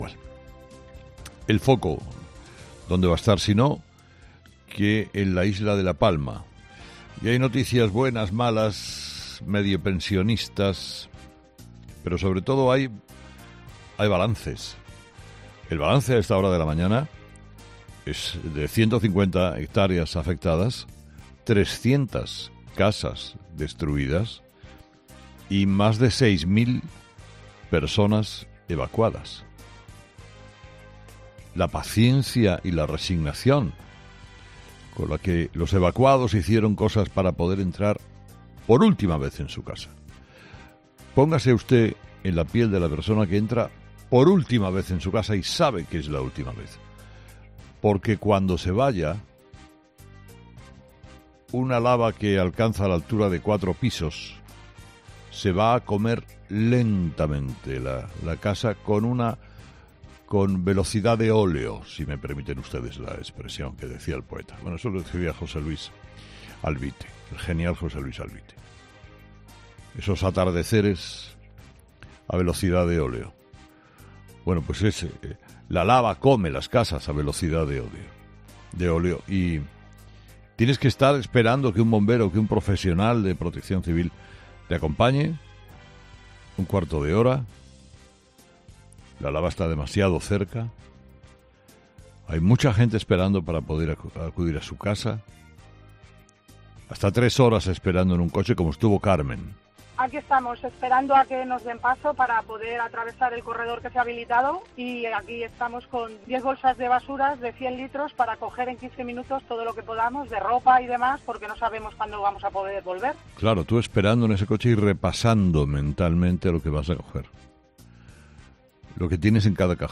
El director y presentador de 'Herrera en COPE' ha comenzado el programa de este jueves analizando las principales claves de la jornada